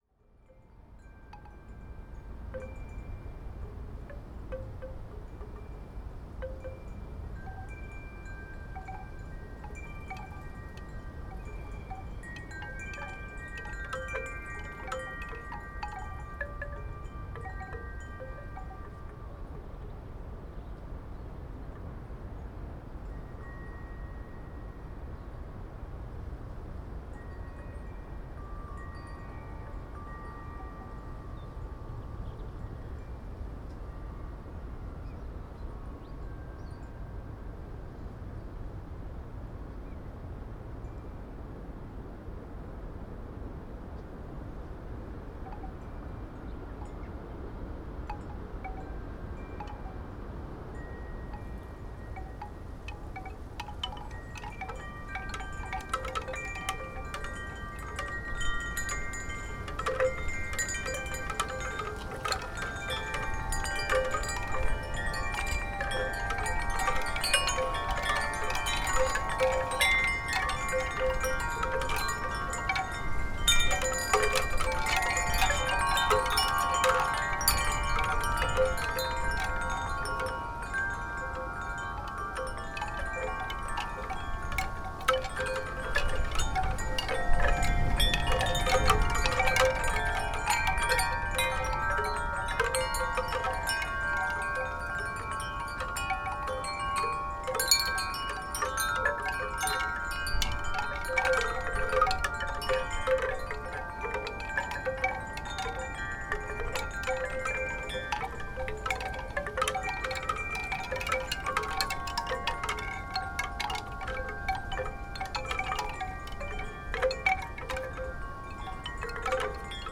Wind Chimes - Bamboo, Pluto, Gypsy Mezzo & Soprano - Hunter's Tor, Teign Gorge - excerpt
Category 🌿 Nature
bamboo chimes Chimes-of-Pluto Devon England February field-recording Gypsy sound effect free sound royalty free Nature